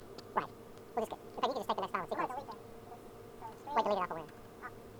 audio48Khz.wav - source file captured from transport stream and wav header added.